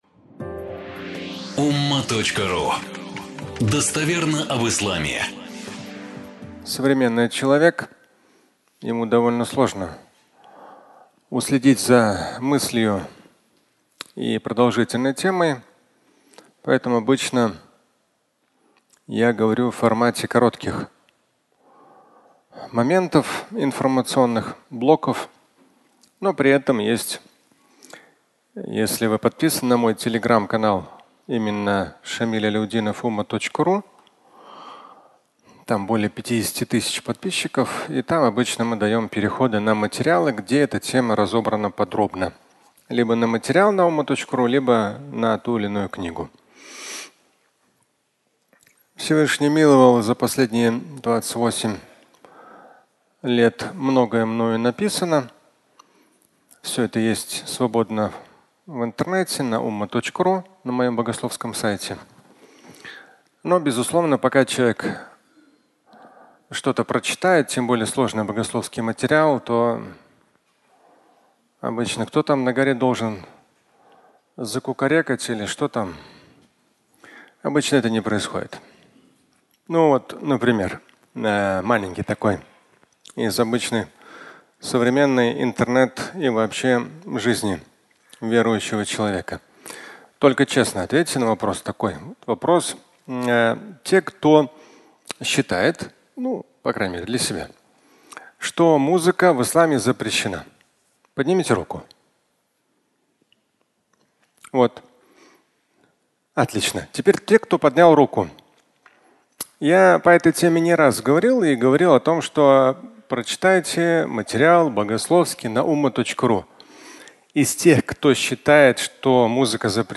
Вирусное распространение (аудиолекция)